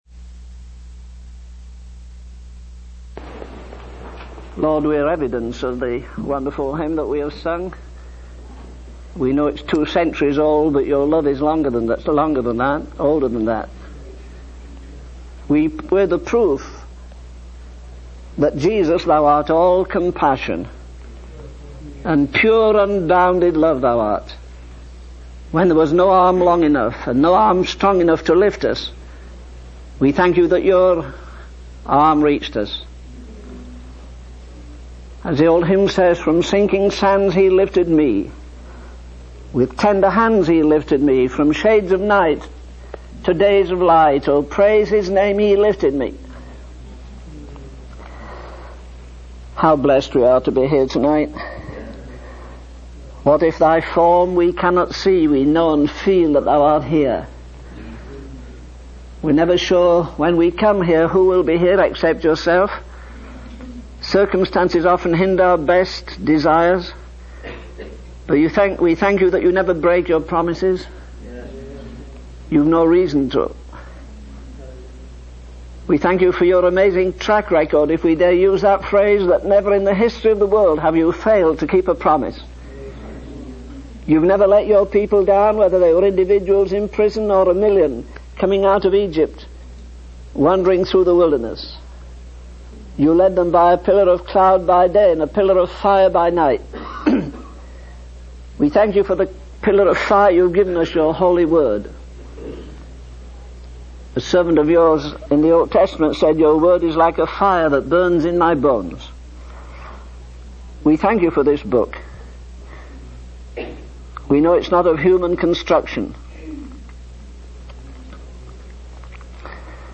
In this sermon, the preacher begins by acknowledging the importance of the Holy Spirit as our guide in understanding the Word of God. He then highlights the urgency of sharing the Gospel with those who are dying without God, emphasizing that their spiritual condition is just as critical as those in remote and uncivilized areas. The preacher also mentions the limitations of worldly solutions and the need for personal transformation through Christ.